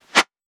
metahunt/weapon_bullet_flyby_04.wav at master
weapon_bullet_flyby_04.wav